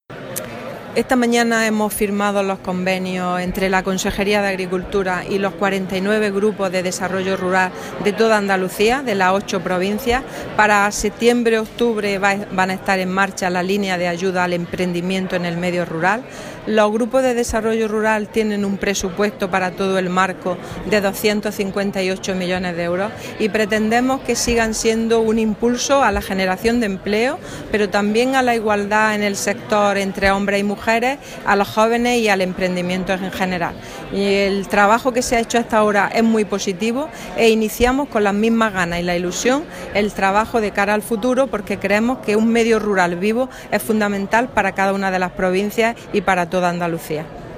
Declaraciones de la consejera sobre la firma del convenio de los Grupos de Desarrollo Rural